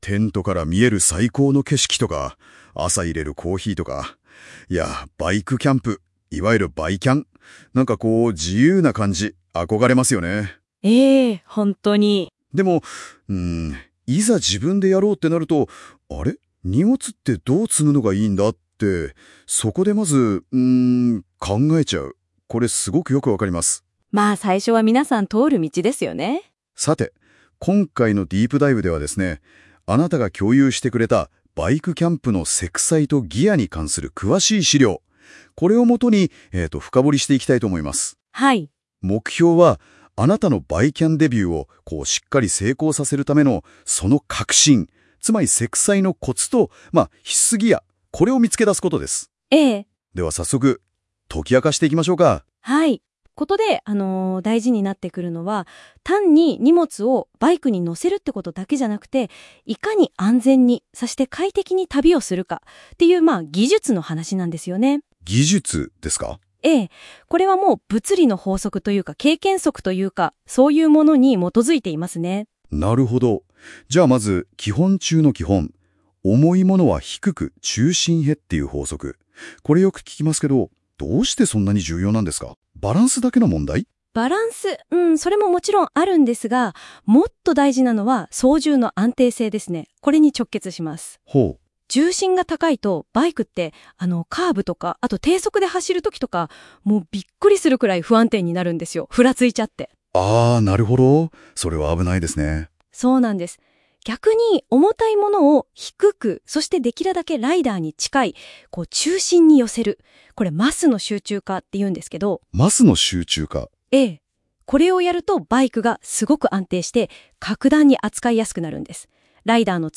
本記事の内容をAIで音声概要を作成しています。※漢字の読み間違いがあります。